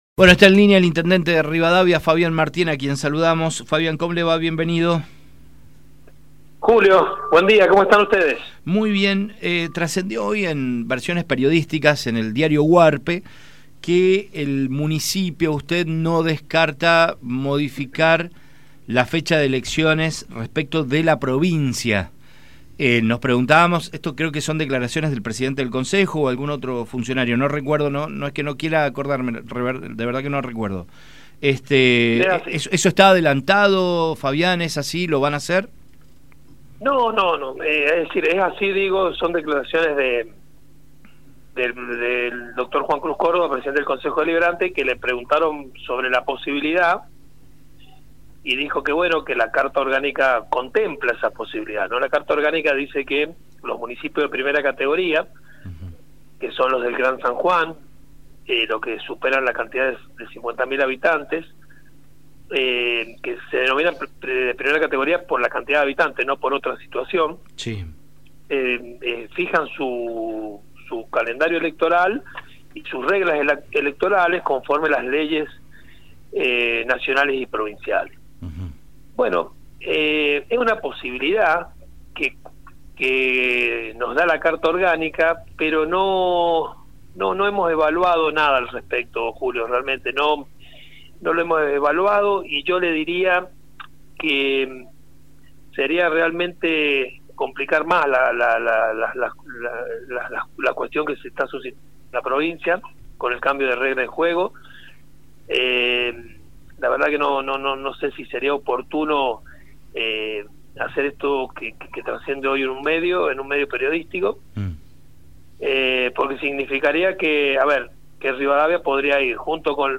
El intendente de Rivadavia y referente de Juntos por el Cambio, Fabián Martín, estuvo en comunicación con los periodistas de «Demasiada Información» por Radio Sarmiento para hablar sobre las dos presentaciones que realizarán ante la Justicia por el nuevo Código Electoral.